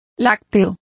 Complete with pronunciation of the translation of milky.